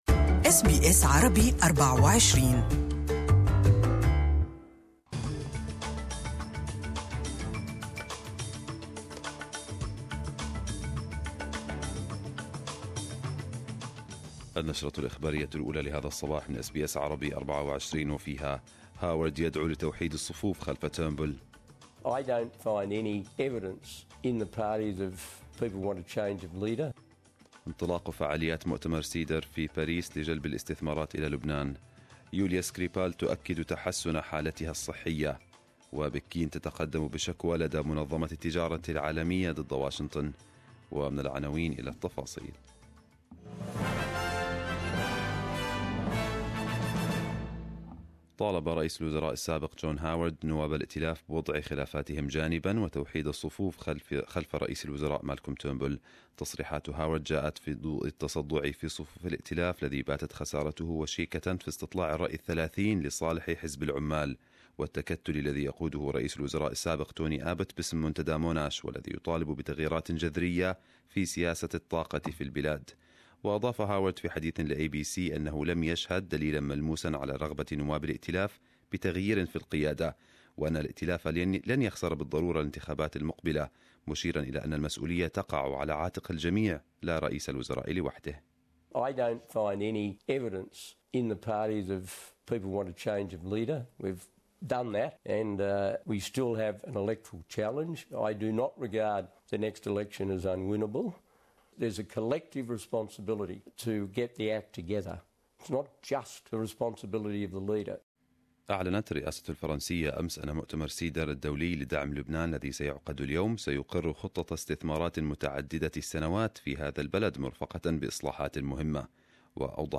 Arabic News Bulletin 06/04/2018